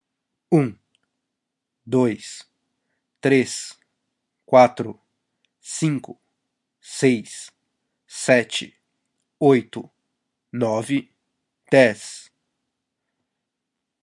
用巴西葡萄牙语从1到10计数，供一般使用。 使用Macbook Pro麦克风录制在车库乐队。